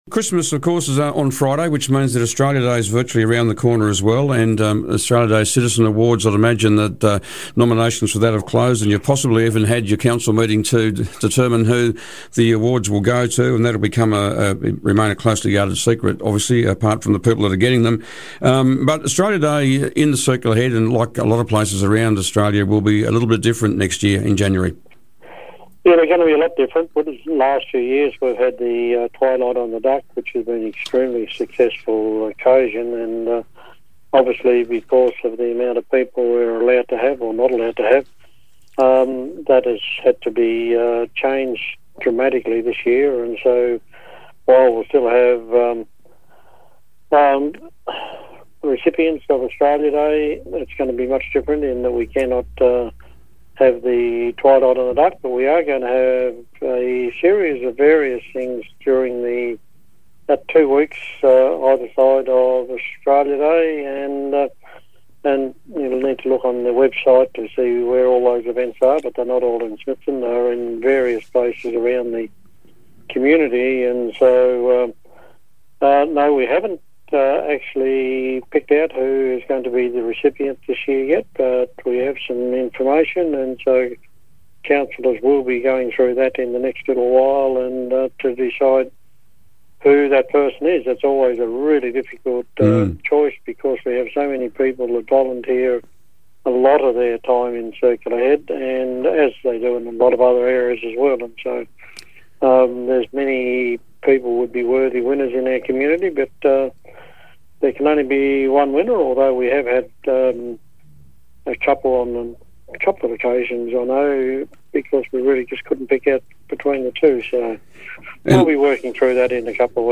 Circular Head Mayor Daryl Quilliam was today's Mayor on the Air.